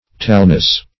Tallness \Tall"ness\, n.